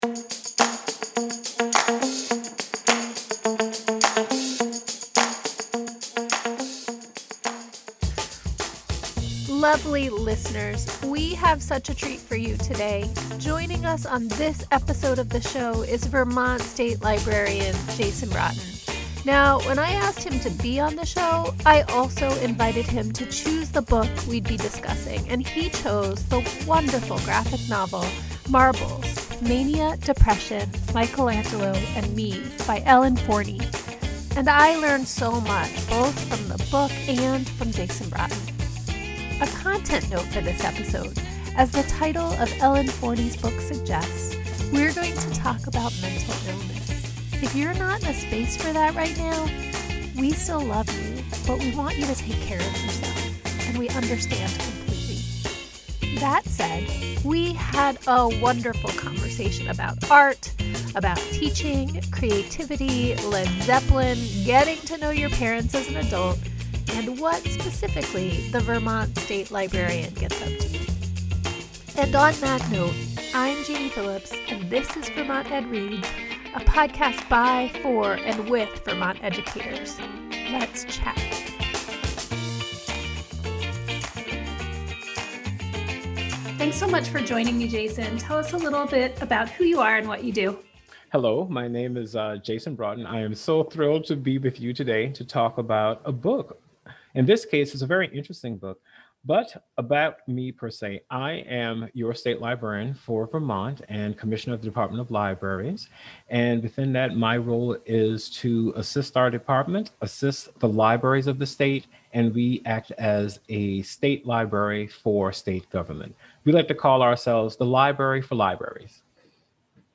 That said, we had a wonderful conversation, about art, about teaching, creativity, Led Zeppelin, getting to know your parents as an adult, and what, specifically, the Vermont State Librarian gets up to.